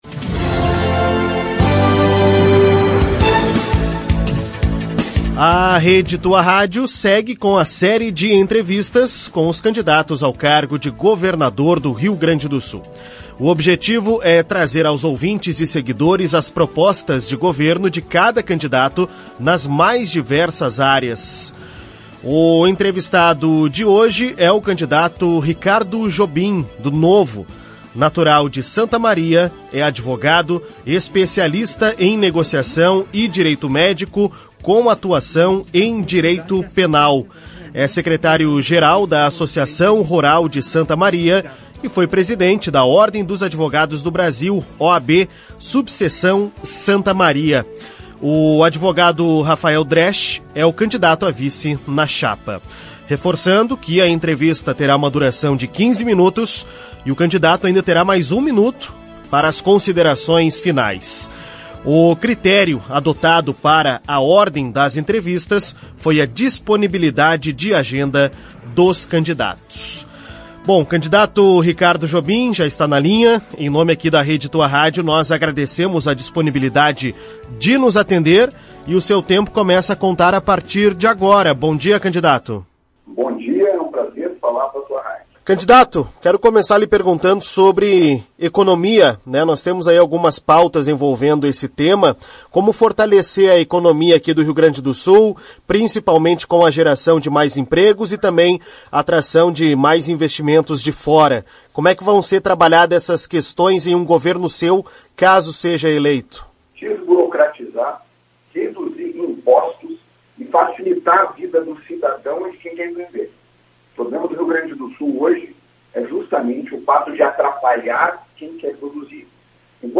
A Rede Tua Rádio segue com a série de entrevistas com os candidatos ao cargo de Governador do Rio Grande do Sul.